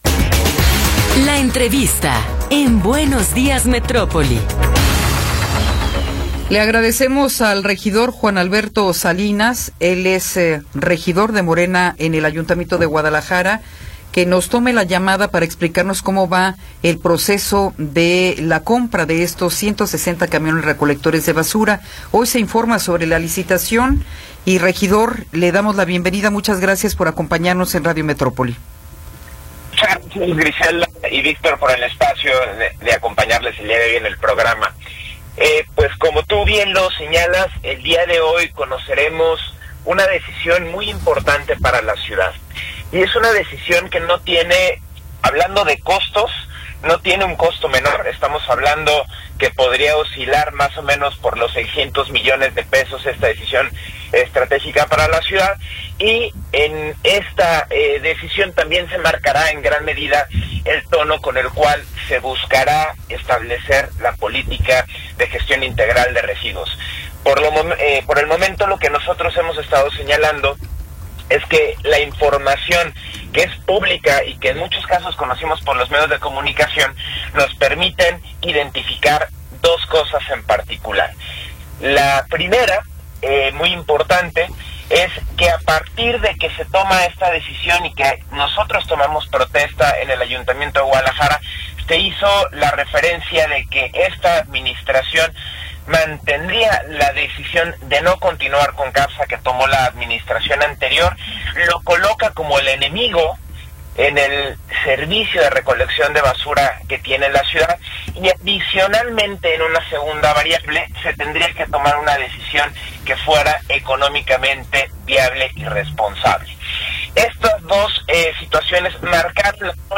Entrevista con Juan Alberto Salinas